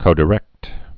(kōdĭ-rĕkt)